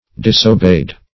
Disobey \Dis`o*bey"\, v. t. [imp. & p. p. Disobeyed; p. pr. &